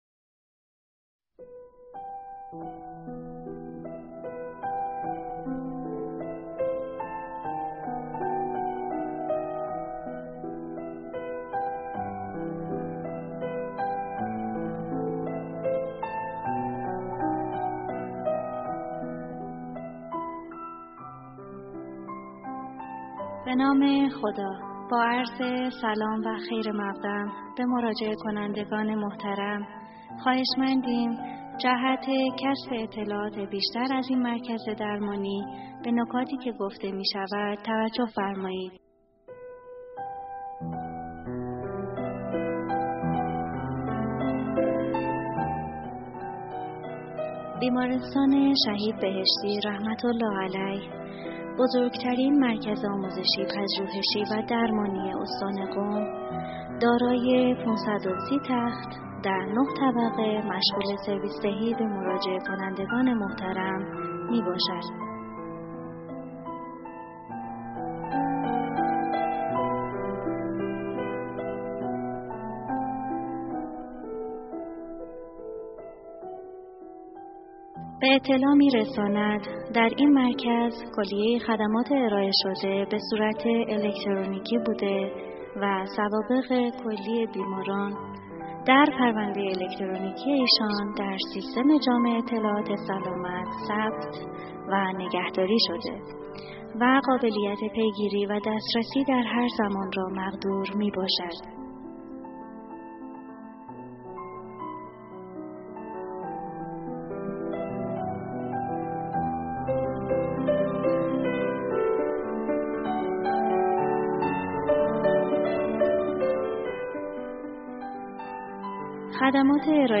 اطلاع رسانی خدمات بیمارستان توسط سیستم پیج، در ساعت ملاقات